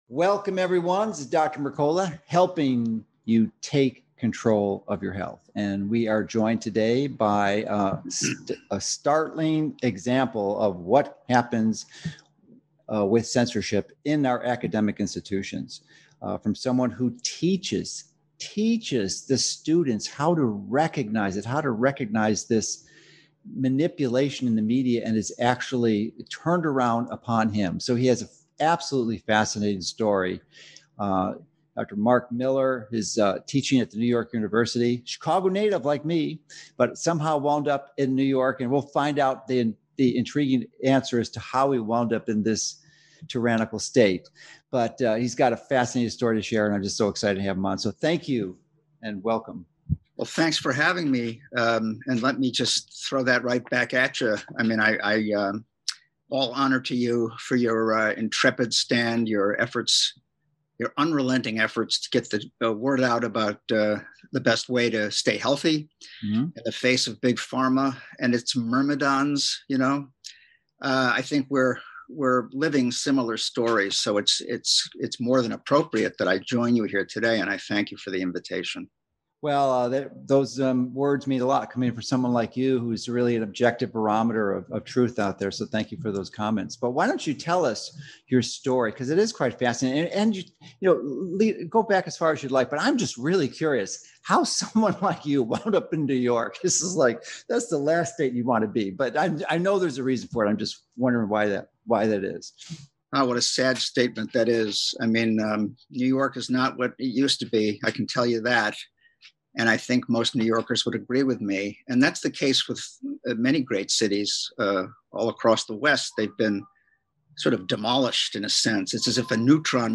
Academic Censorship- Interview